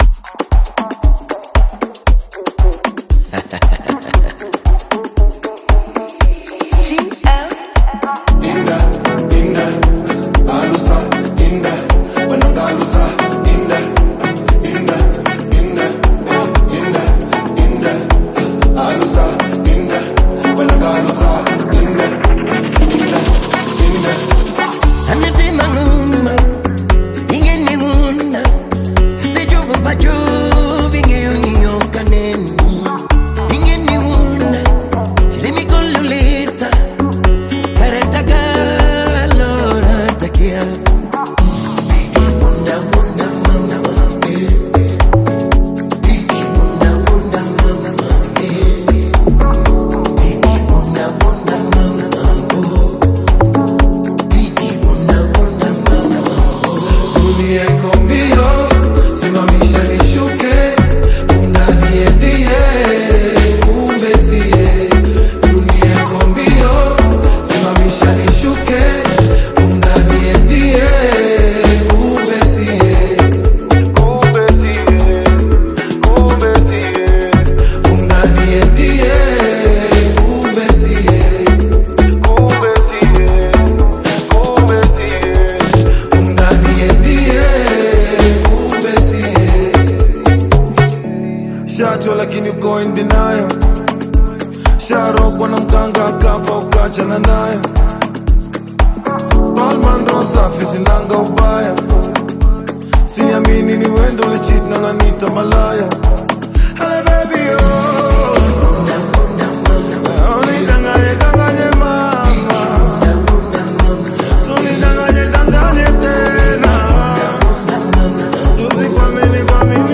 infectiously catchy hit